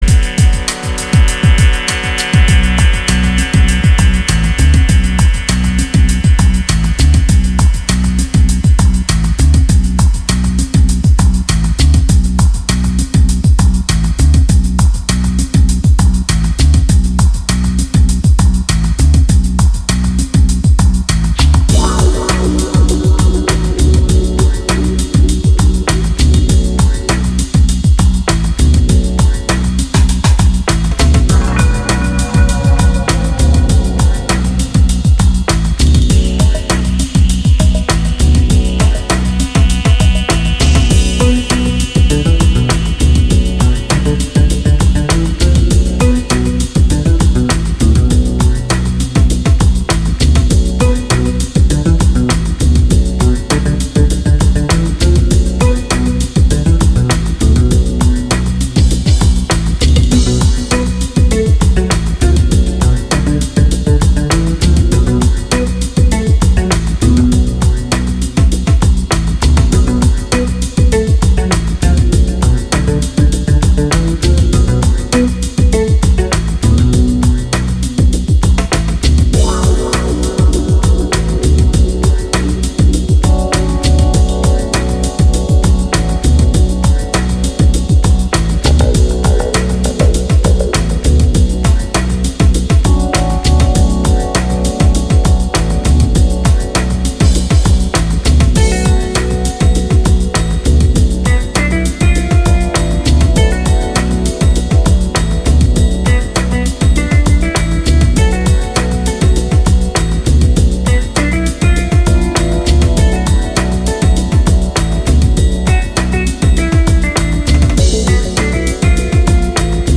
Жанр: Chilout